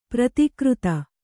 ♪ pratikřta